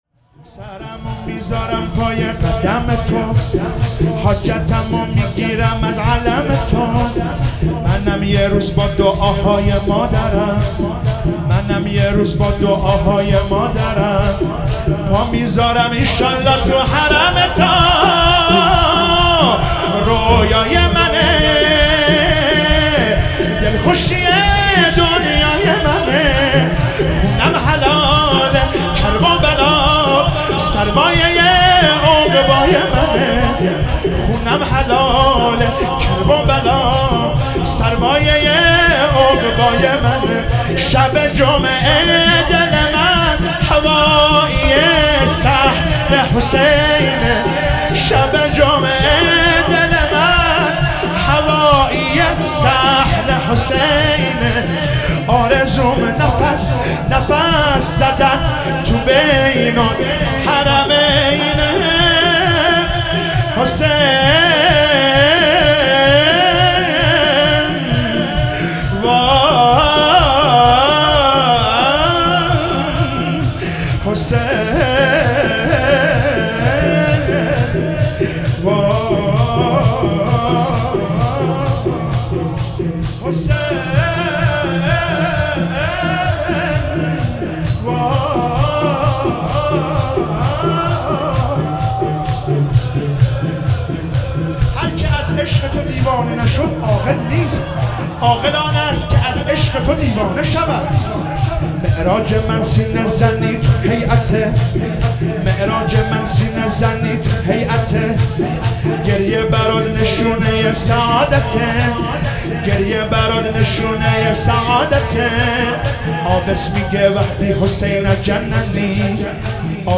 شور - سرمو میزارم پای قدم تو